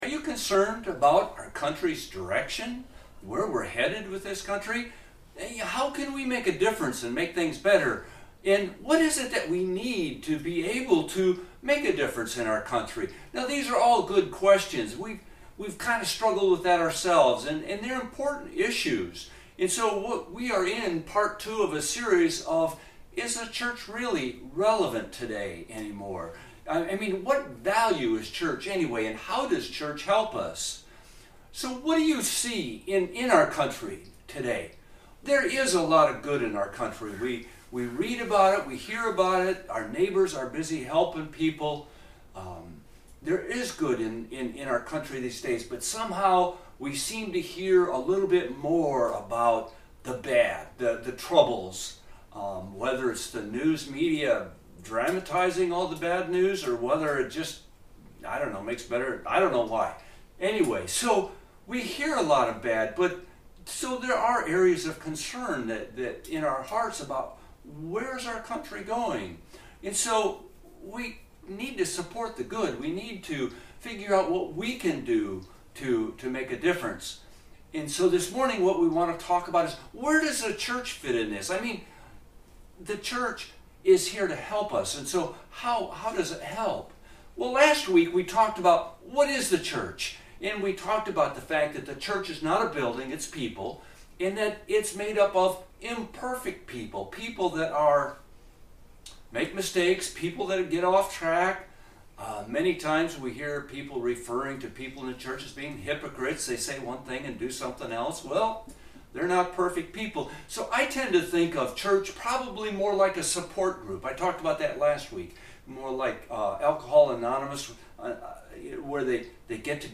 Mark 1:21-24 Service Type: Worship-online Are you concerned about the direction our country seems to be headed?